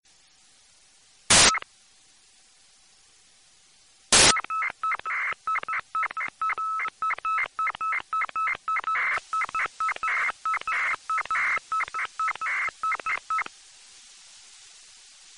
UnknownDataChirp_Sound.mp3